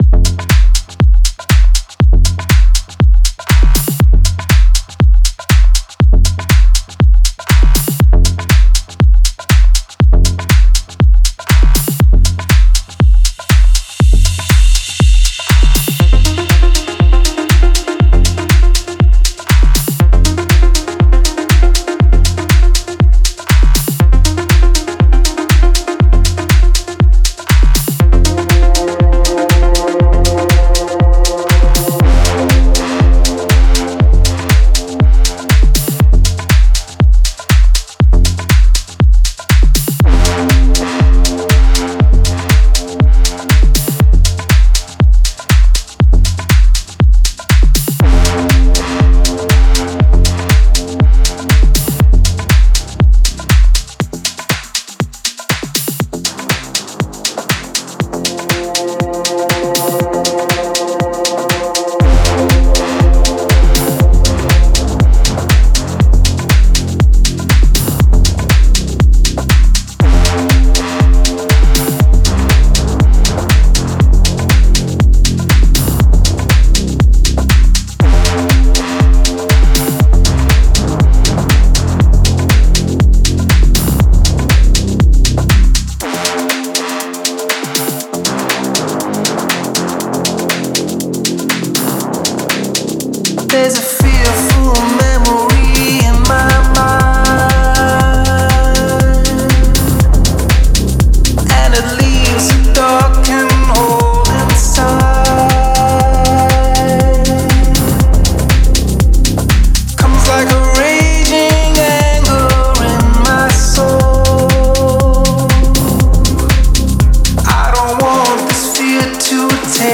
Genre: Electronic, Synthwave.